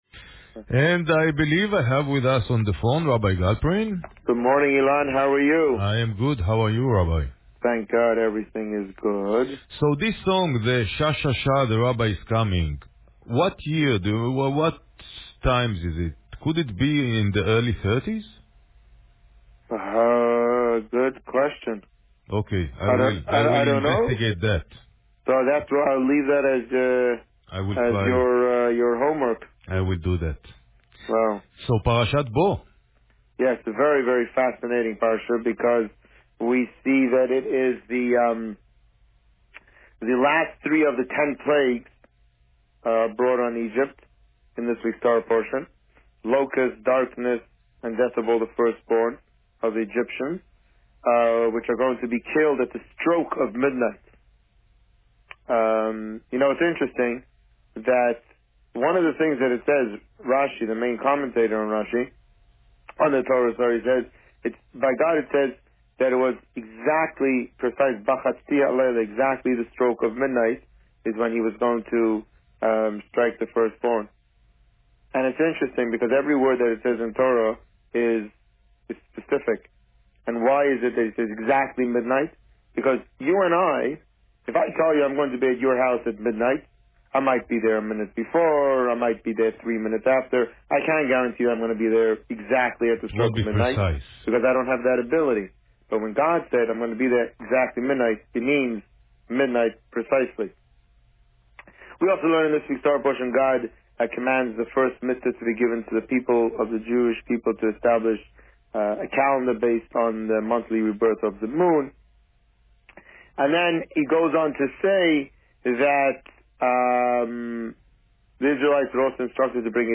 This week, the Rabbi spoke about Parsha Bo. Listen to the interview here.